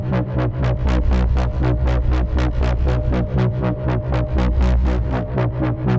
Index of /musicradar/dystopian-drone-samples/Tempo Loops/120bpm
DD_TempoDroneA_120-B.wav